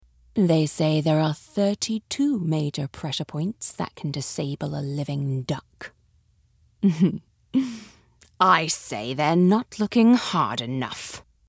Animation V/O - Standard British Accent
Fearless Badass Femme Fatale